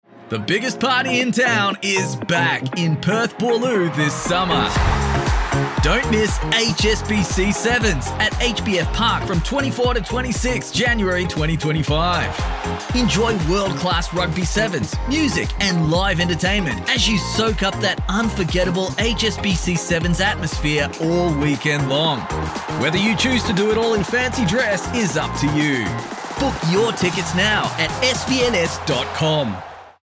Male
Radio Commercials
Words that describe my voice are Deep, Tenor, Credible.